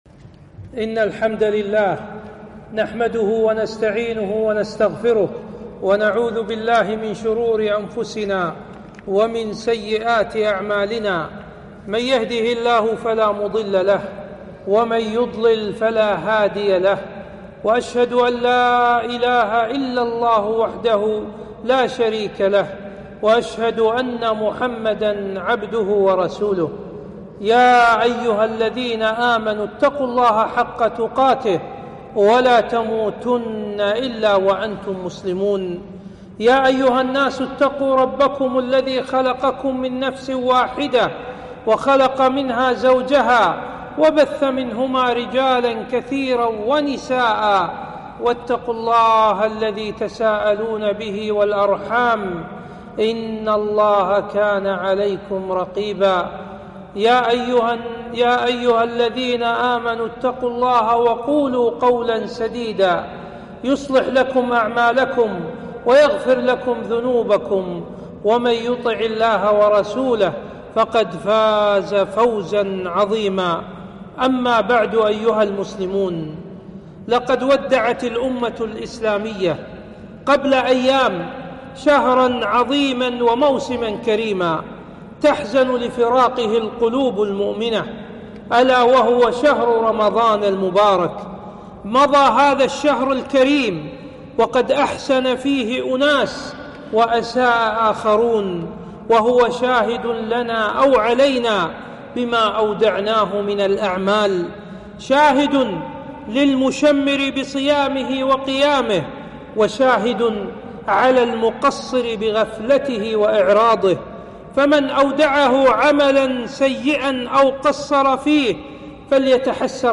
خطبة - المداومة على الطاعة